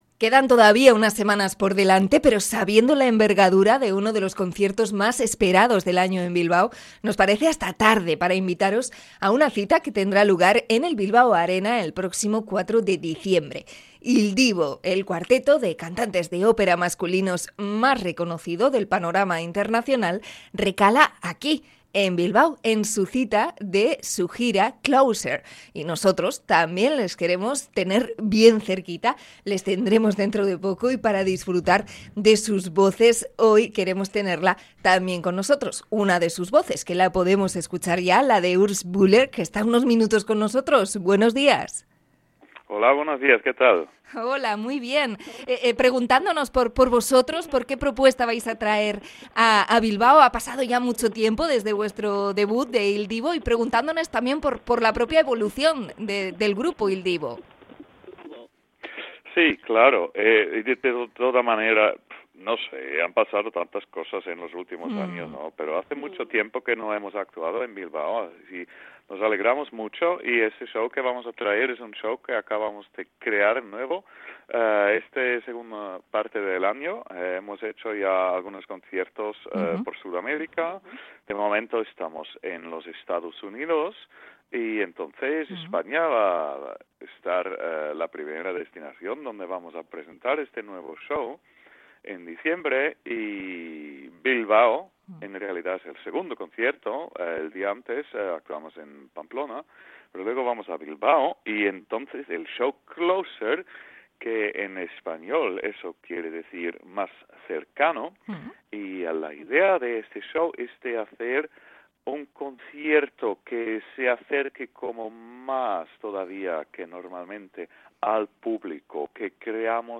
Entrevista con el miembro de Il Divo Urs Bühler